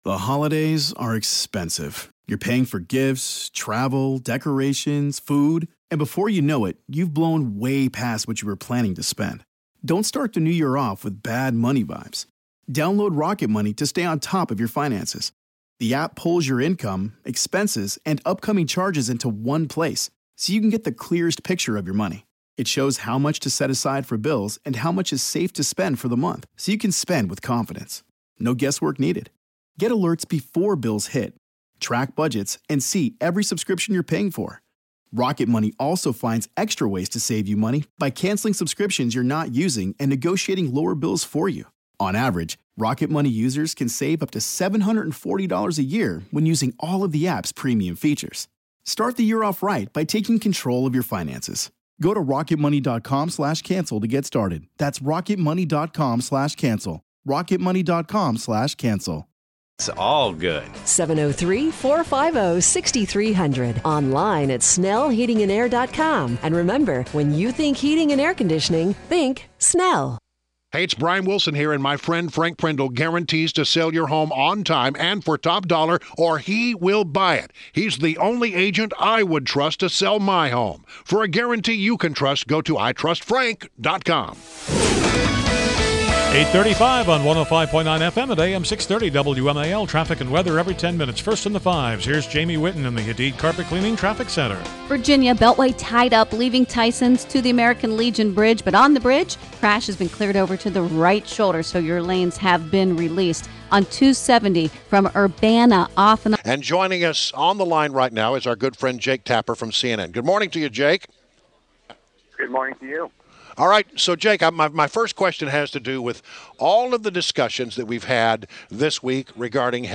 INTERVIEW — JAKE TAPPER – the host of the CNN’s “The Lead” and “State of the Union”